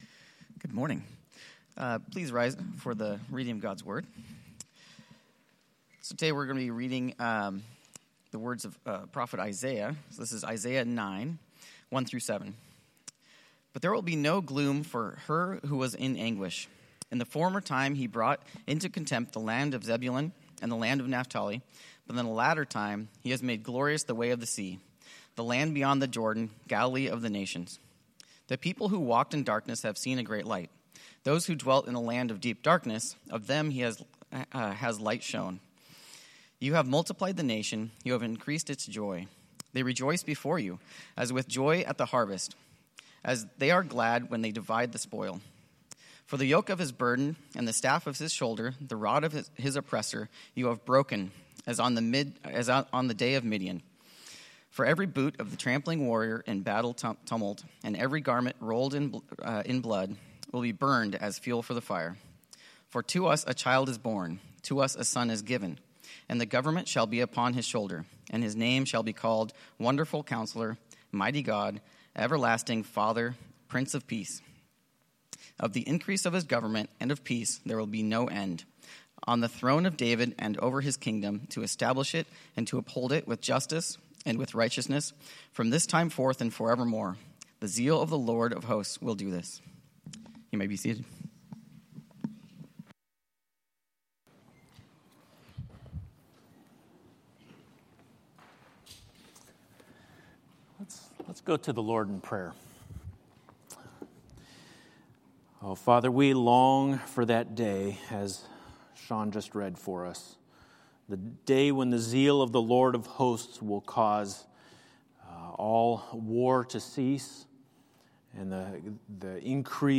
Sermons - Grace Church - Pasco